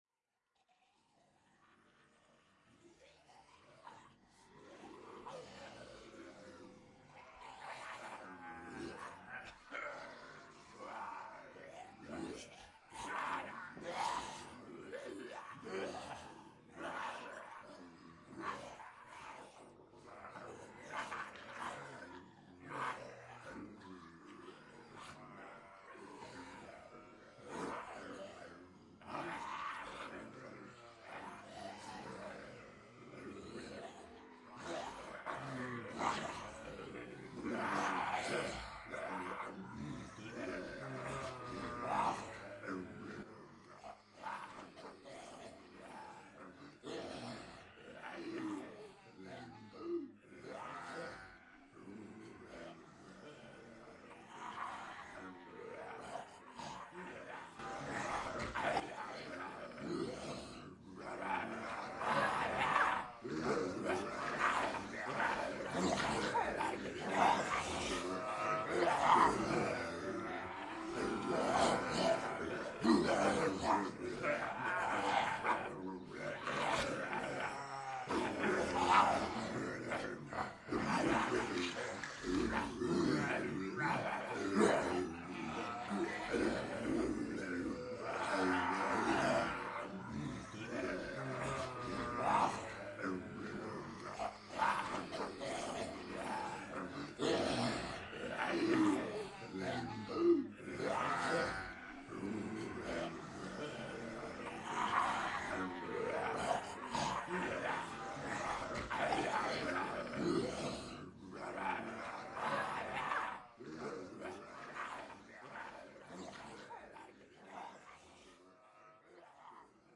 描述：Multiple people pretending to be zombies, uneffected.
标签： ensemble group snarl roar voice solo monster horror deadseason undead zombie
声道立体声